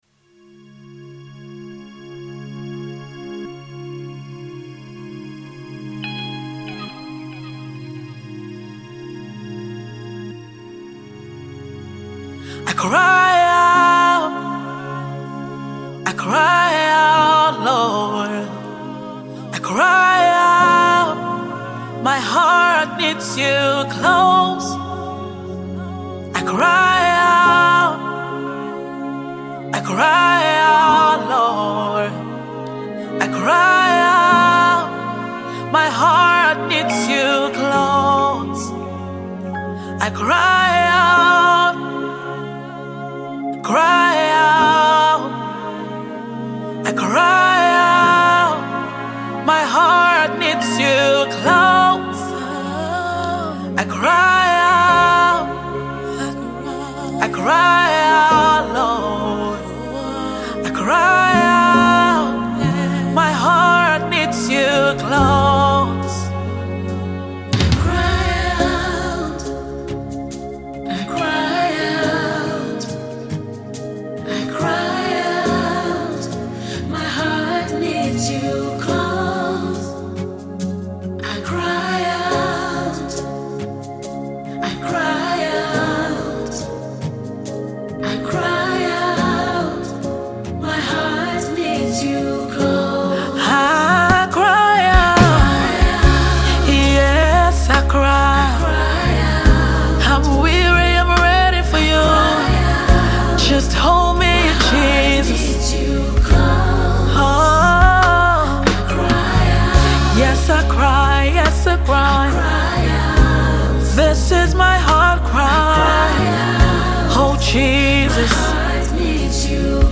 worship leader